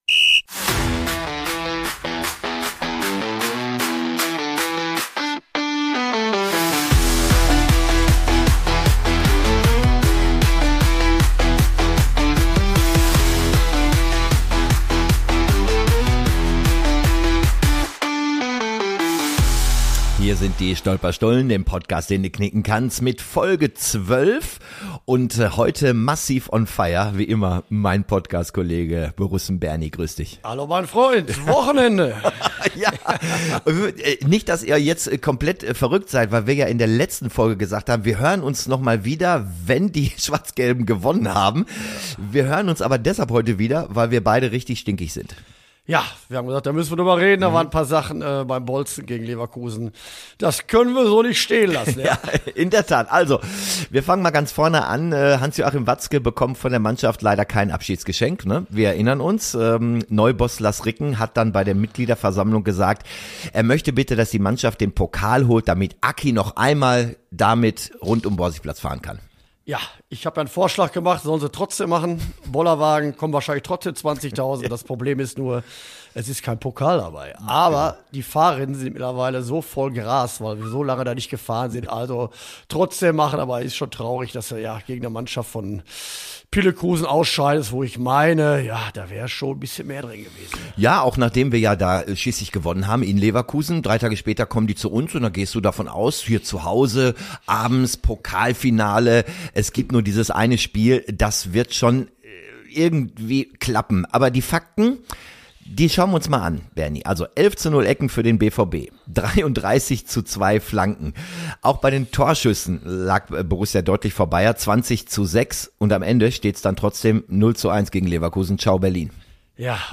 Humorvoll. Emotional.